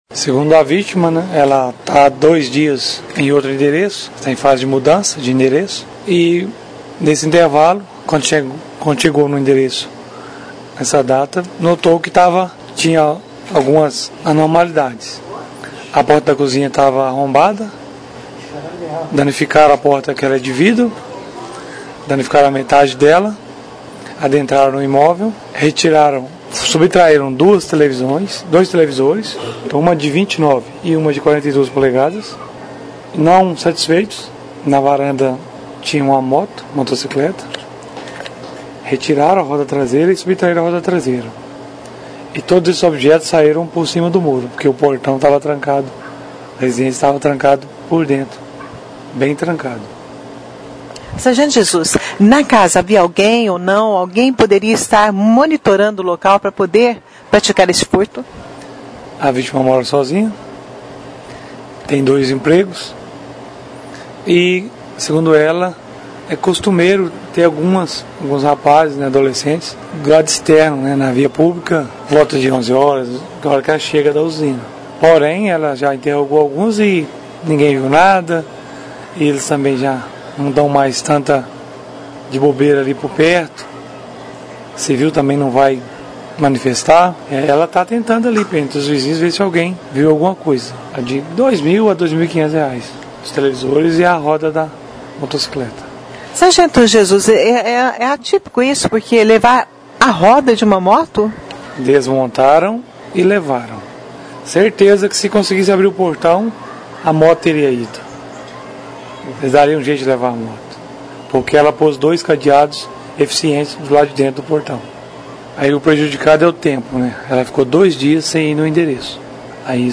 (Clique no player abaixo e ouça a entrevista). A Polícia Militar foi ao Bairro Frutal III, onde registrou o furto de dois televisores e ainda a roda de uma motocicleta que estava na casa.